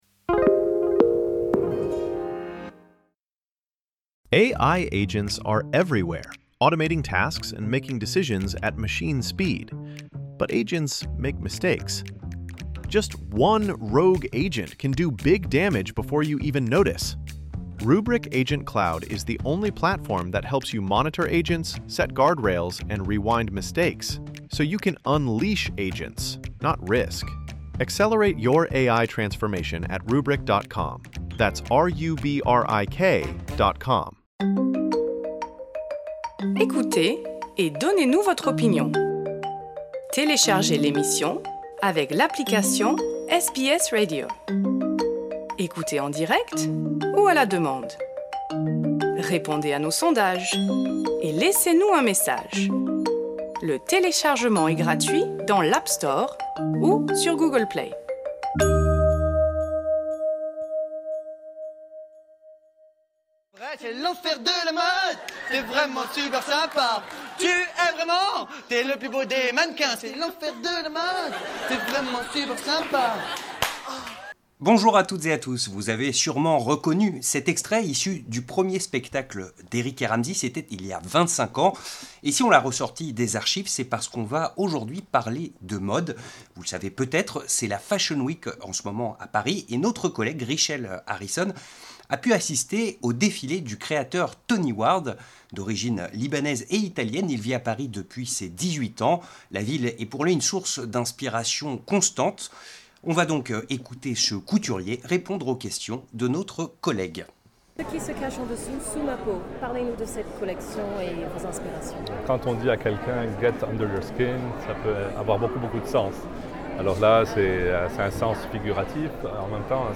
Paris Fashion Week: Interview du couturier Tony Ward
A l'occasion de la Fashion Week à Paris, nous avons pu rencontrer le couturier Tony Ward, il nous a accordé un entretien après son défilé.